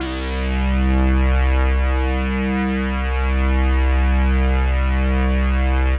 C2_trance_lead_2.wav